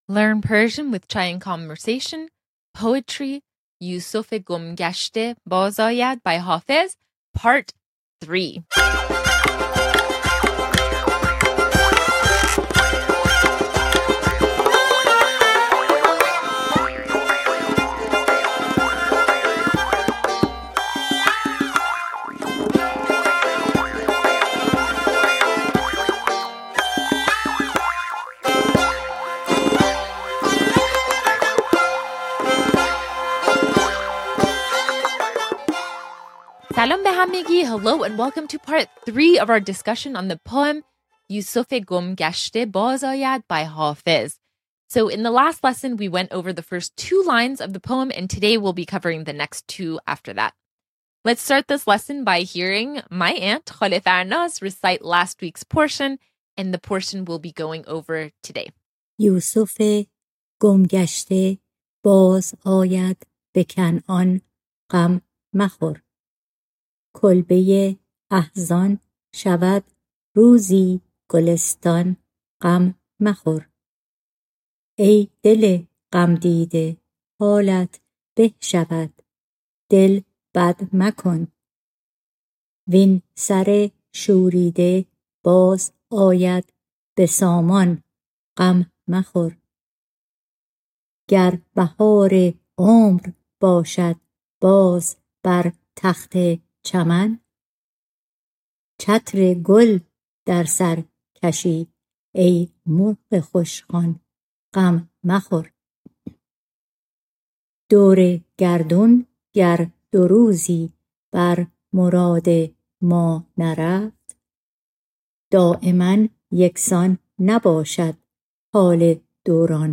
In this lesson, we go over the individual words and phrases in the next four lines of the poem yoosofé gom gashté by Hafez, which refer broadly to ...